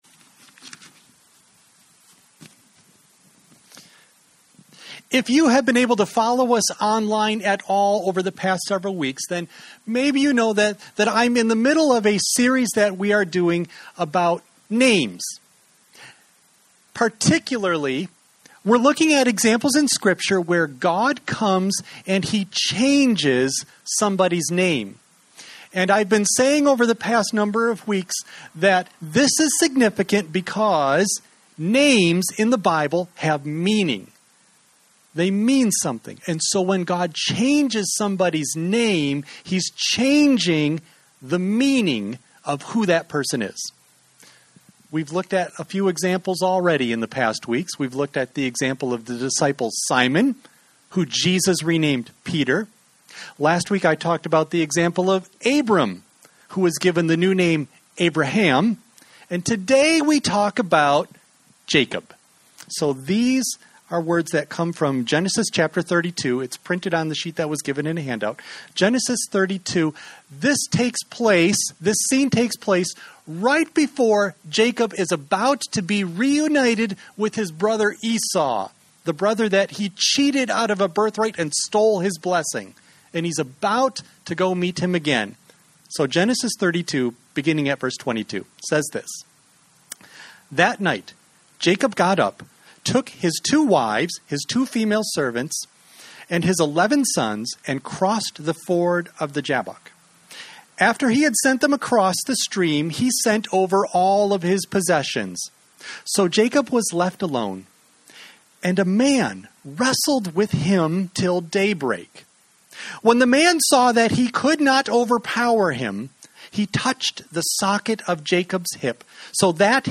Audio only of message